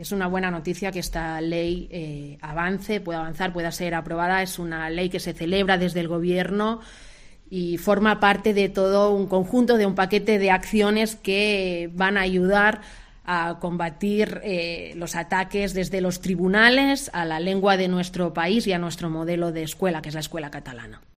La portavoz del Govern Patricia Plaja, valora el dictamen del Consell de Garanties Estatutaries